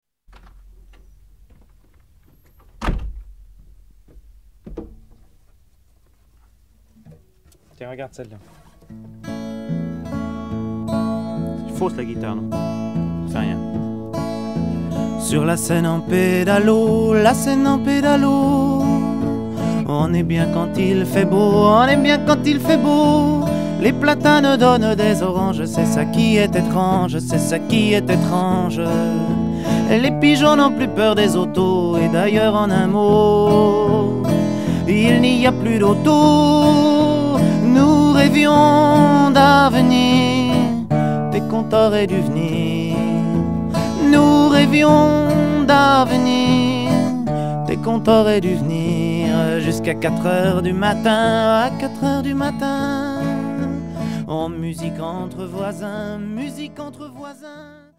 version acoustique